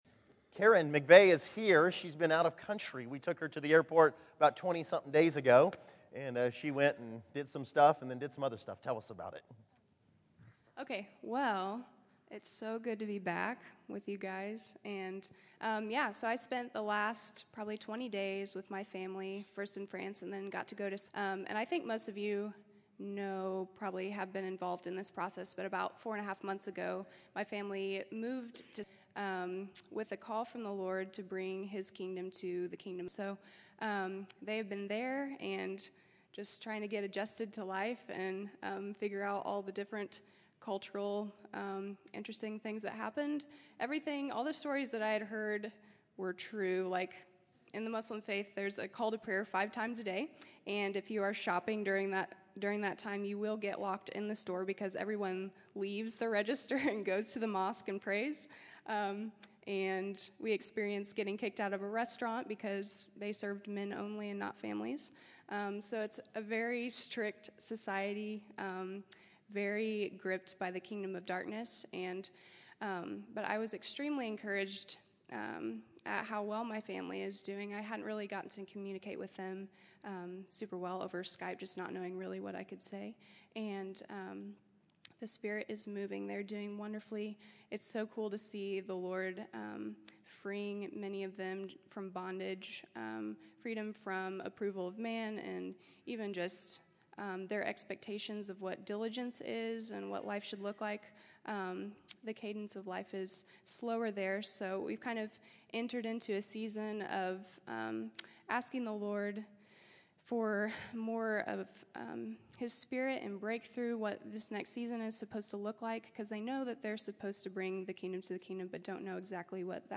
January 12, 2014      Category: Testimonies      |      Location: El Dorado